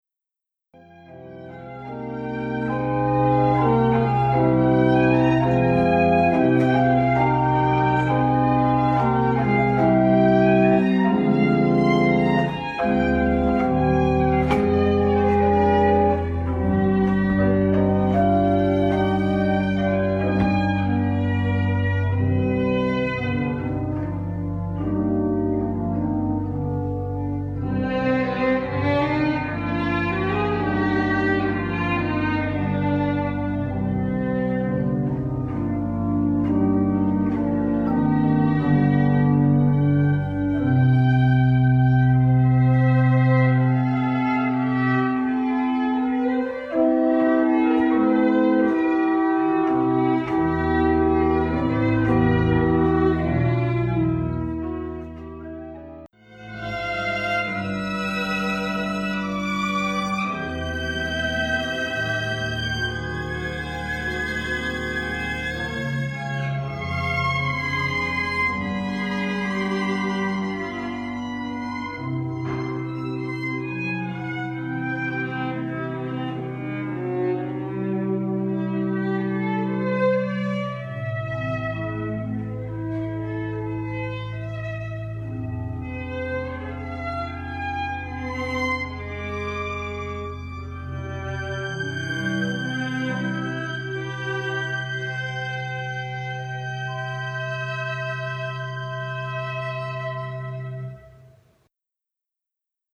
Viljandista noin 20 kilometriä Pärnun suuntaan osutaan aivan tien varressa Kõpun kirkolle.
Kõpun urut ovat pienet (8 äänikertaa) mutta vivahteikkaat.